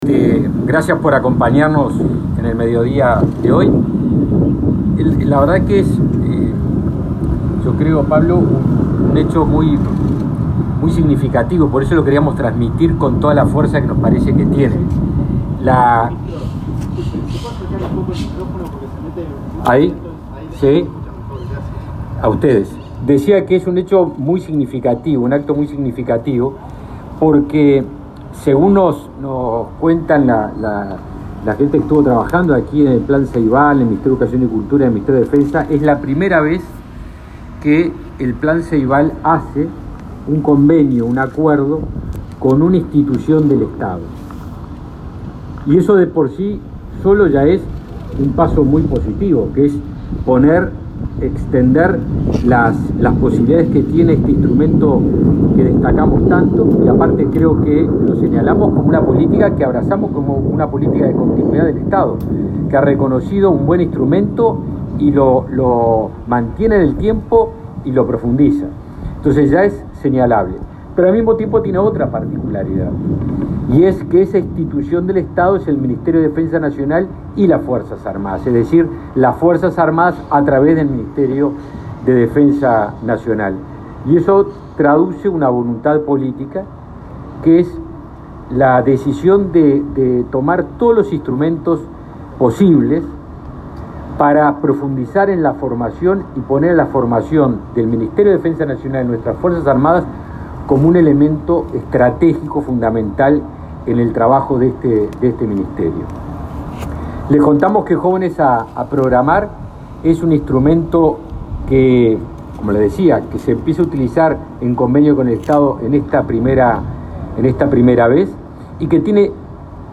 Declaraciones de Javier García sobre convenio del Ministerio de Defensa con Plan Ceibal